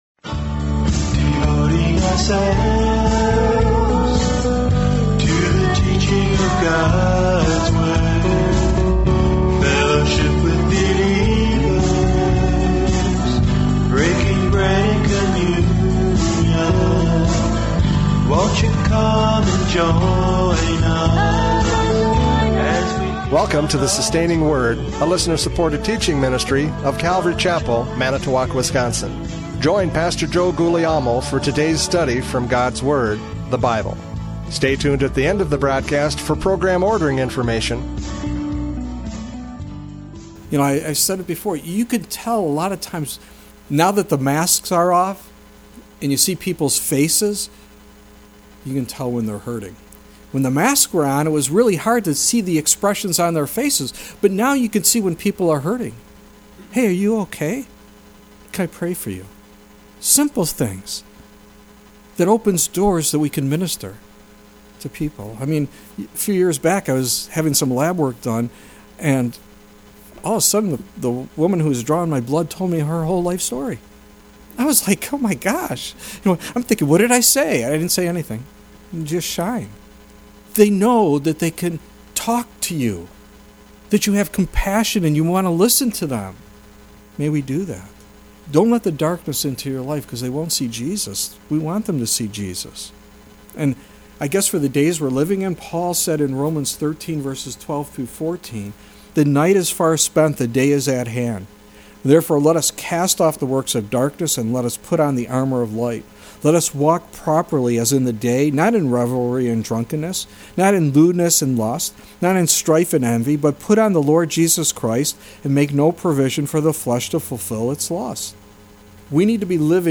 John 8:12-20 Service Type: Radio Programs « John 8:12-20 Light and Darkness!